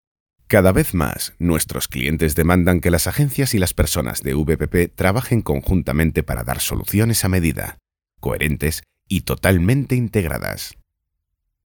Voz amable y cercana para documentales y anuncios, galán para películas o series y mediana edad para realities y voice-overs.
kastilisch
Sprechprobe: eLearning (Muttersprache):
Friendly and close voice for documentaries and commercials, gallant for movies or series and middle age for realities and voice-overs.